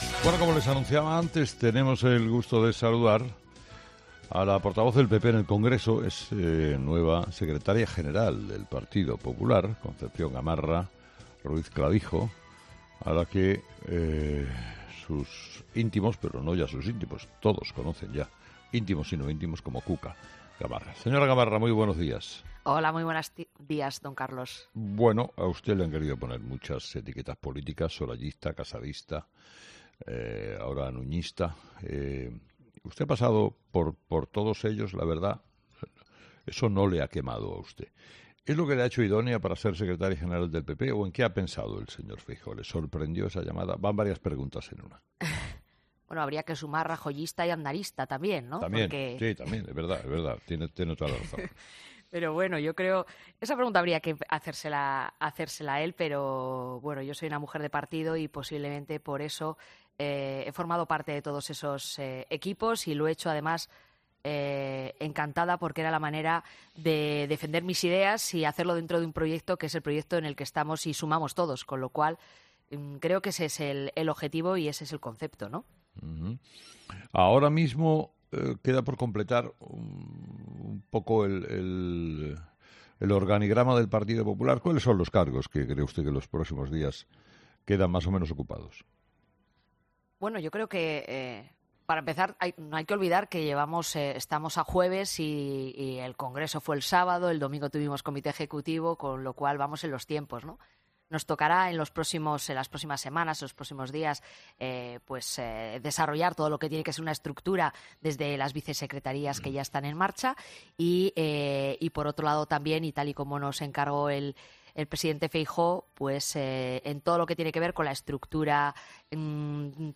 Reunión Sánchez-Feijóo, Vox y el origen de la nueva era del PP, claves de la entrevista de Gamarra en COPE